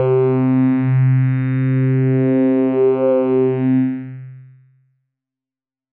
Organesq Pad C3.wav